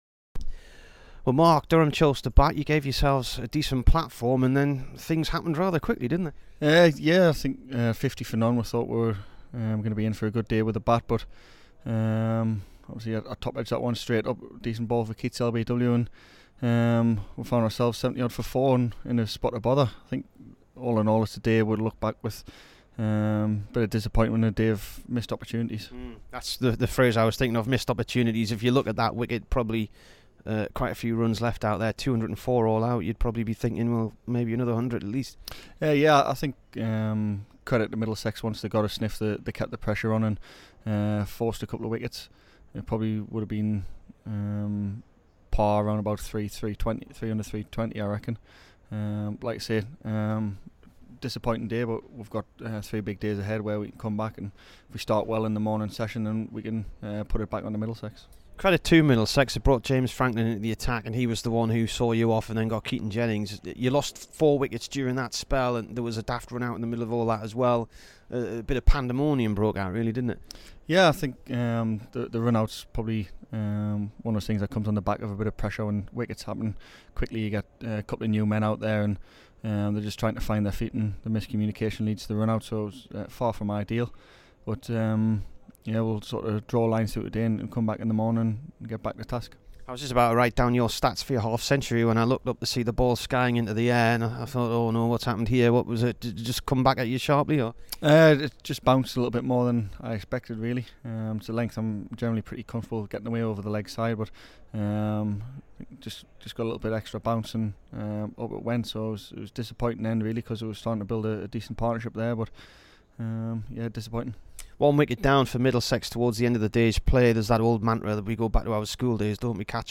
Mark Stoneman int
Here is the Durham opener after his 46 on day one of the Middlesex match.